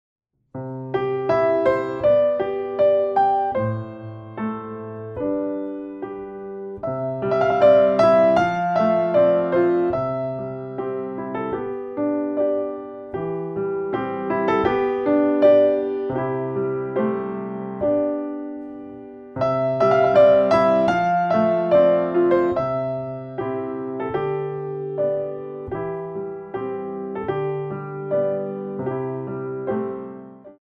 2 bar intro 4/4
64 bars + balance